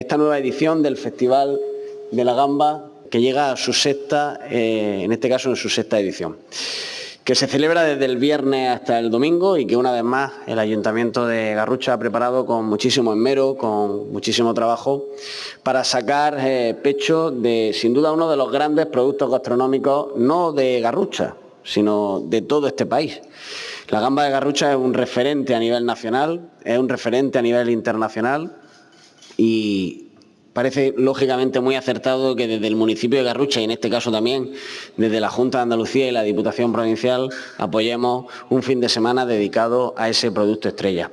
Durante la presentación, el diputado de Promoción Agroalimentaria, Carlos Sánchez; el delegado de Turismo, Cultura y Deporte de la Junta en Almería, Juan José Alonso; el alcalde de Garrucha, Pedro Zamora; y el concejal de Comercio, Turismo y Juventud de Garrucha, Pablo Fernández; han destacado que este festival se ha consolidado como un escaparate de sabor, tradición y promoción turística, con un programa pensado para todos los públicos y que refuerza la proyección de la Gamba Roja de Garrucha como símbolo de calidad y seña de identidad de la provincia.
21-10_gamba_diputado.mp3